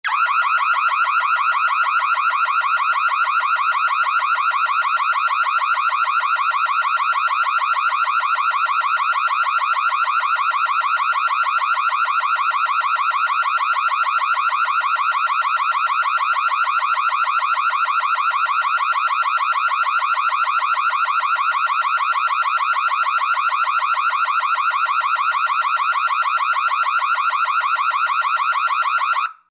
Звуки пожарной сирены, тревоги